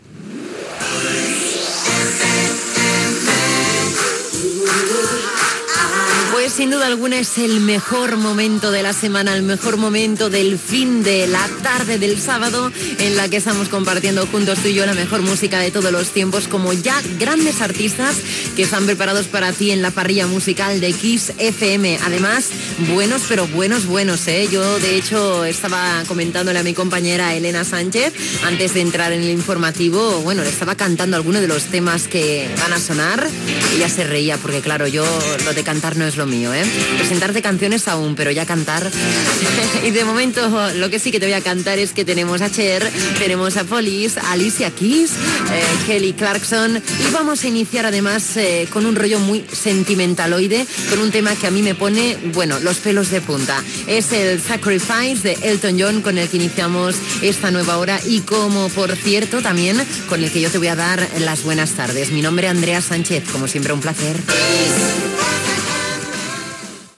Indicatiu del programa, propers artistes que sonaran i tema musical Gènere radiofònic Musical